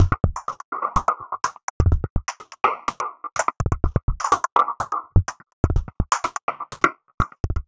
tx_perc_125_fiddly.wav